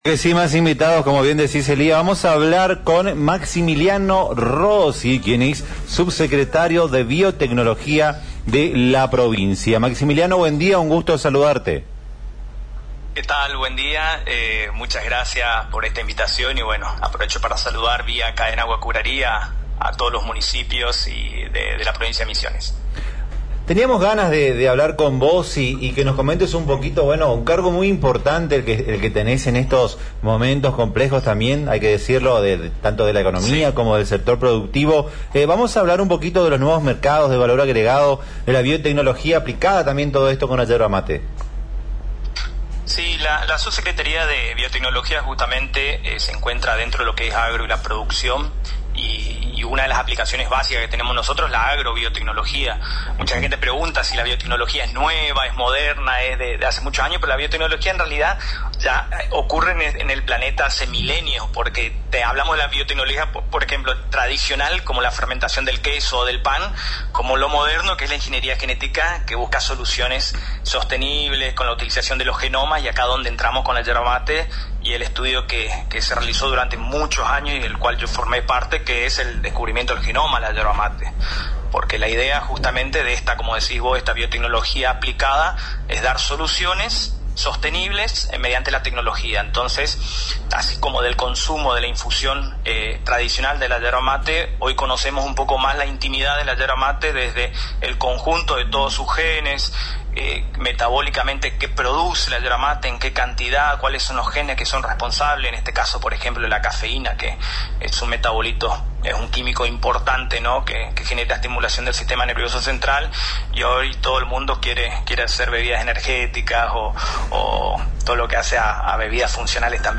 En este sentido, el subsecretario de Biotecnología del Ministerio del Agro y la Producción, Maximiliano Rossi, dialogó con la cadena de radios ANG y comentó sobre las tareas que se desarrollan en su área, como la generación de nuevos mercados de valor agregado y la aplicación de la biotecnología en distintos sectores productivos, incluida la yerba mate.